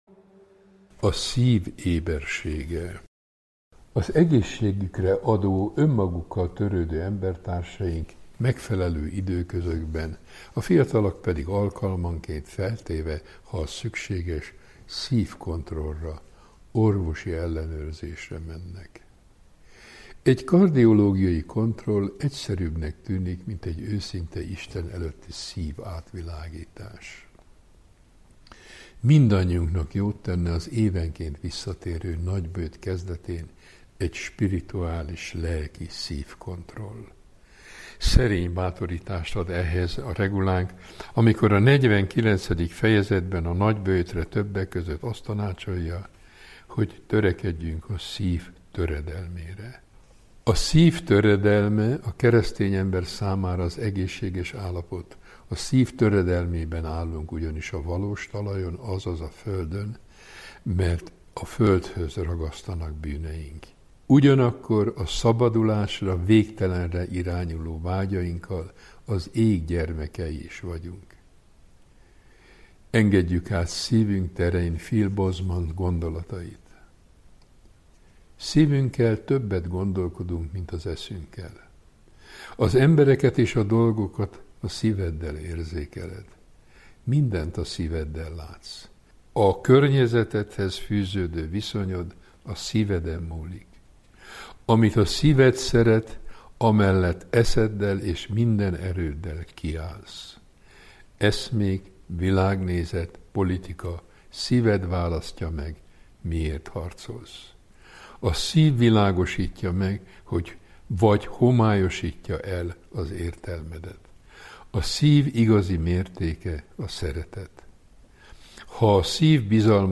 Szent Benedek öröksége Várszegi Asztrik emeritus pannonhalmi főapát tolmácsolásában